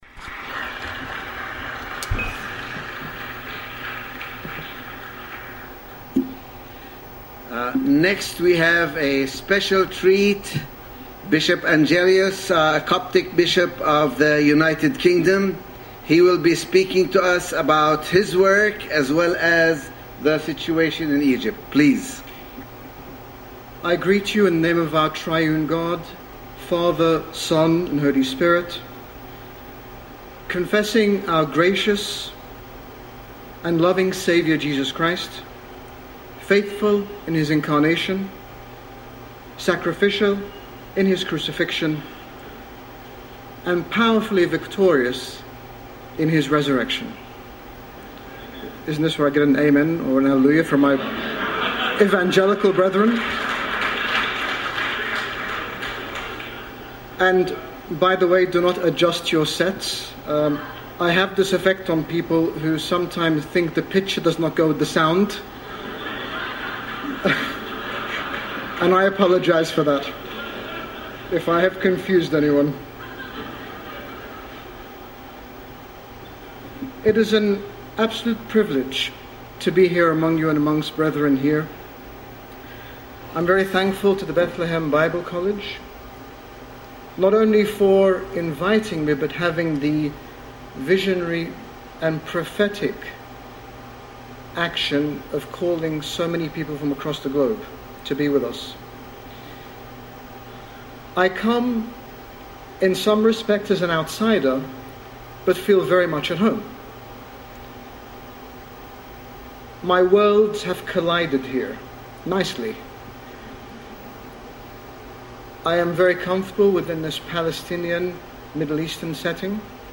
In March 2014 His Grace Bishop Angaelos, General Bishop of the Coptic Orthodox Church in the United Kingdom delivered an address at the Christ at the Checkpoint conference in Bethlehem Bible College. His Grace spoke regarding matters concerning Christians in Egypt, and in particular the Coptic Orthodox presence and contribution there both historically and in light of recent events.